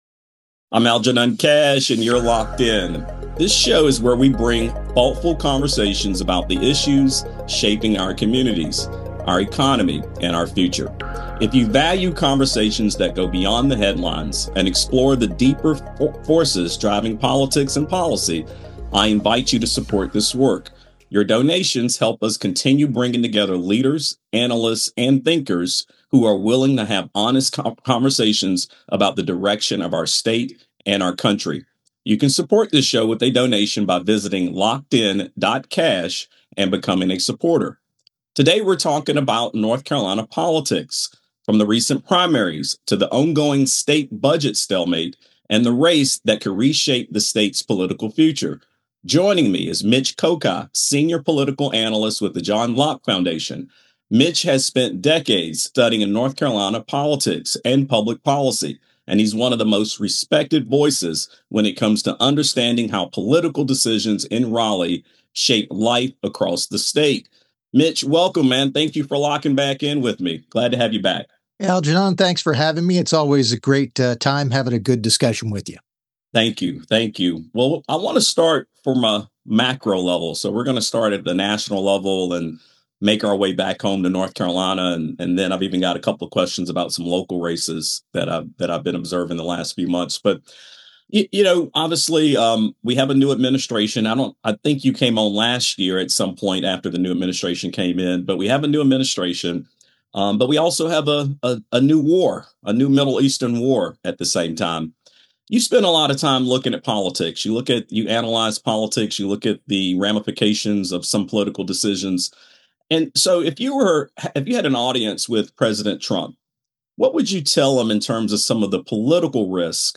for a focused conversation on the current state of North Carolina politics 2026 and what it means for voters, policymakers, and the broader economy. We break down what the primary results signal about voter sentiment, why lawmakers have struggled to pass a state budget, and the key policy debates influencing decisions in Raleigh.